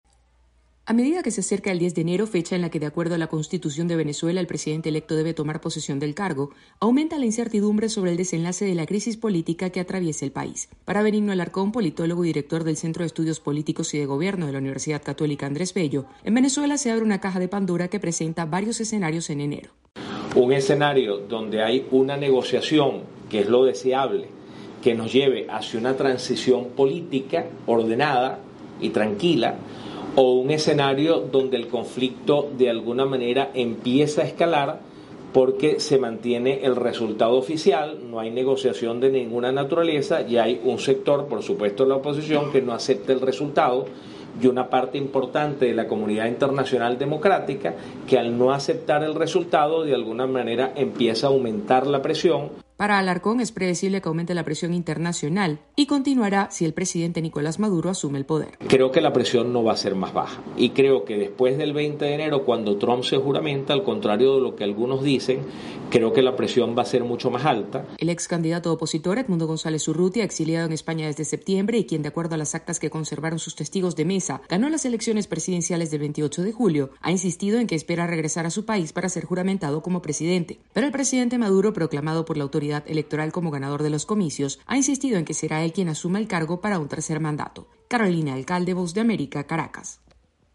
AudioNoticias
Dos grandes escenarios se presentan en Venezuela ante la inminente toma presidencial el 10 de enero. Desde Caracas informa